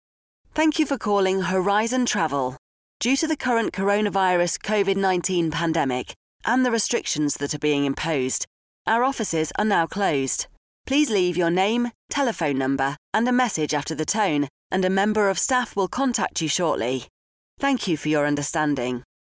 Actrice Voix Off Femme | Anglais, Grande Bretagne
une voix off féminine professionnelle, saura captiver votre audience avec sa voix chaleureuse et son interprétation expressive.